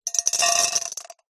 Звуки человека
Полный рот выбитых зубов выплевывается в металлическую миску стоматолога